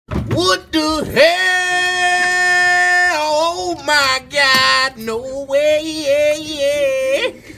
What The Hell Meme SFX